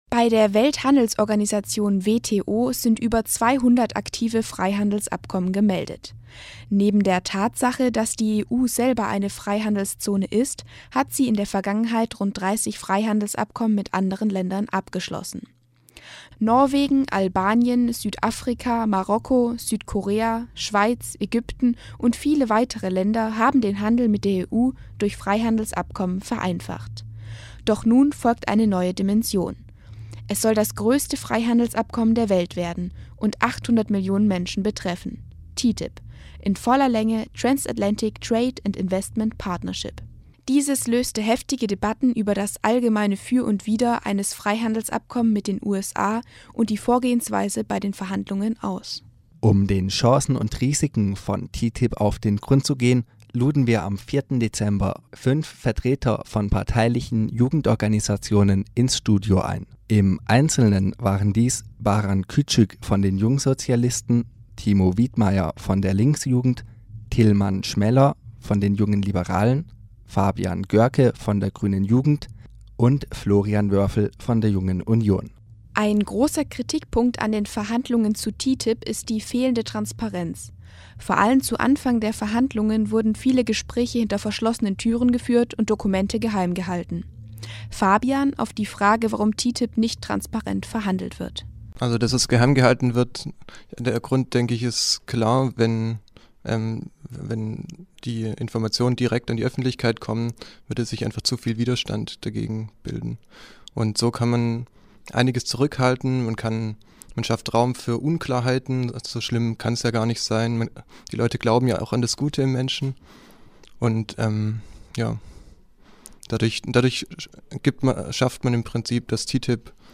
TTIP - was spricht dafür, was dagegen? Eine Diskussion
TTIP-Diskussion mit Vertretern parteilicher Jugendorganisationen: Am 04.12. führte das Jugendradioprojekt YouEdiT eine Diskussion über Chancen und Risiken des Freihandelsabkommens TTIP.
Aus diesem Grund hat die europäische Jugendredaktion YouEdiT Befürworter und Gegner des Freihandelsabkommens zu einer Diskussion im Freien Radio Wüste Welle eingeladen.